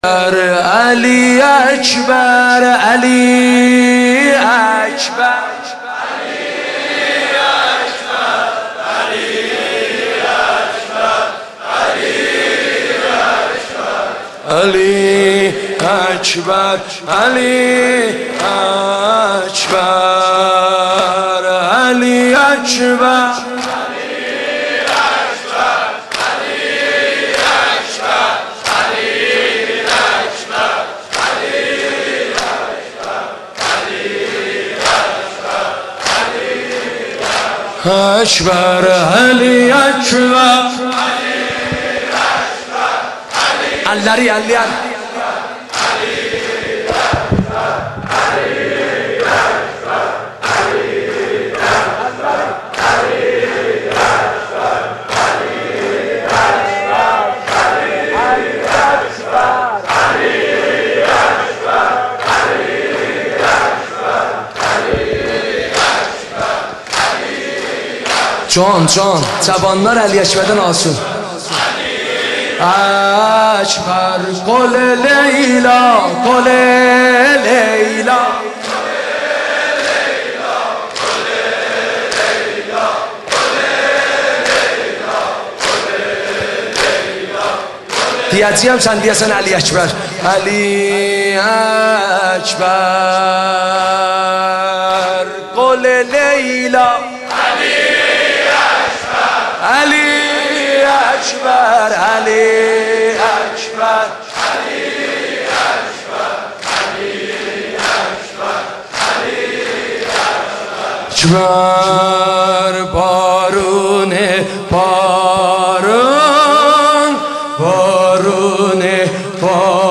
شب هشتم محرم مداحی آذری نوحه ترکی